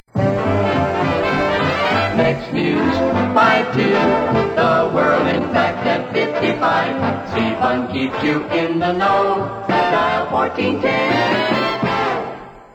Next News at 55